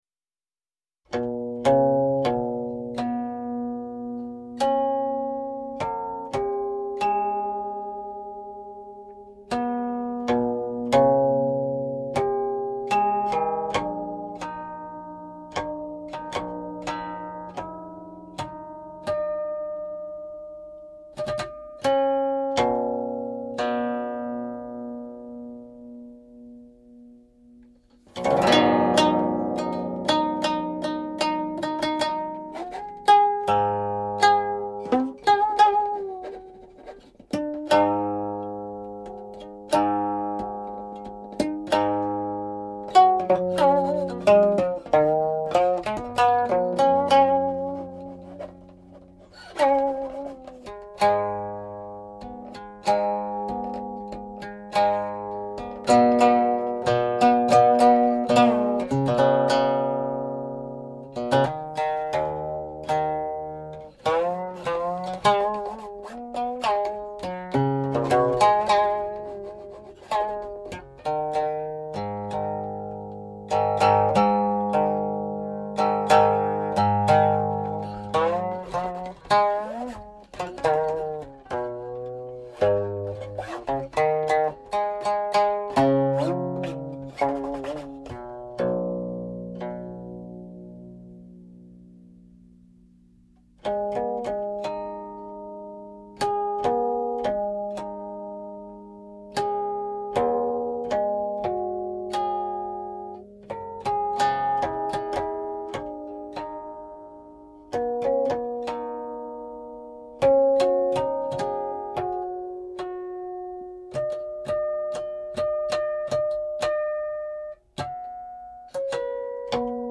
The most noticeable characteristic of Section 1, which is in harmonics, is the number of harmonics played at the eighth node (hui). These notes all have an intonation slightly different from harmonics played in the more common positions.6 Most early versions after 1425 changed the tablature so that the number of these "odd" notes was decreased and eventually eliminated.
Eight sections; titles are from Zheyin Shizi Qinpu 11
(06.19) -- End of piece (no harmonics indicated11)